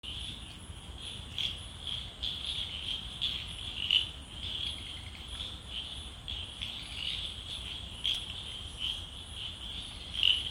Wynaad Brown Eared Shrub Frog Scientific Name: Pseudophilautus Wynaadensis